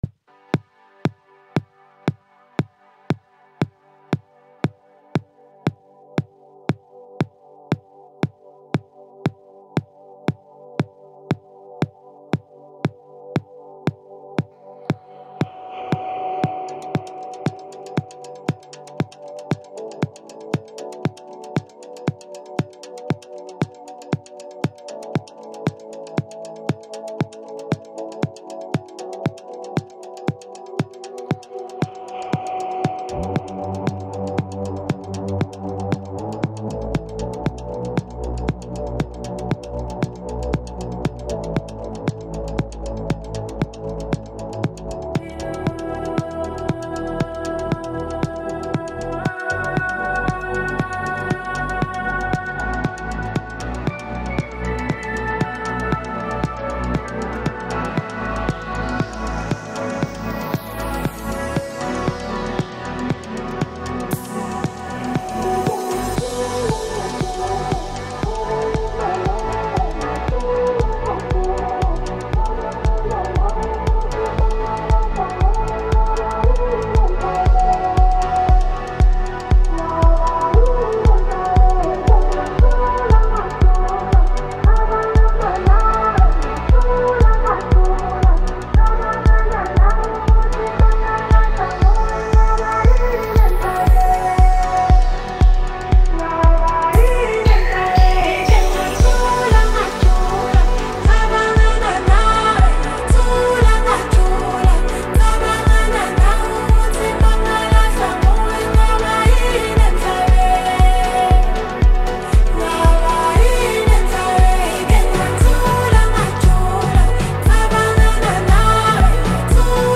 Amapiano, Lekompo